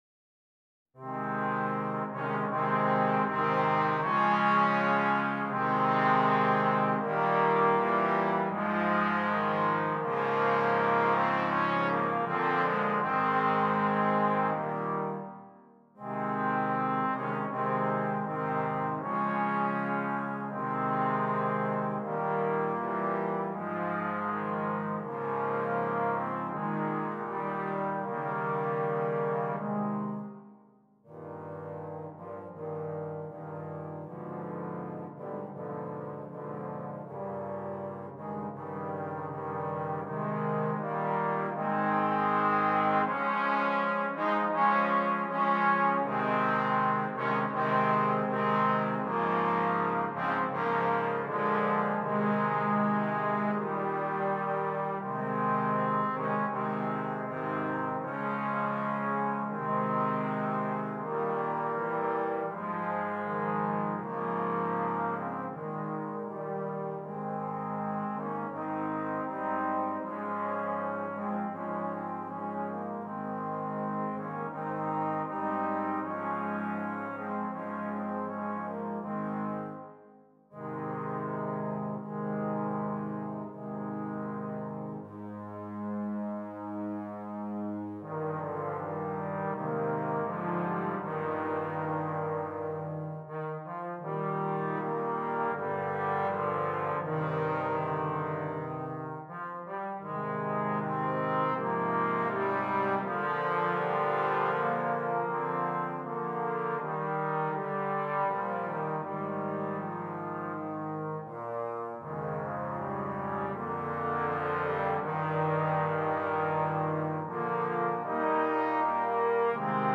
4 Trombones
Difficulty: Easy-Medium Order Code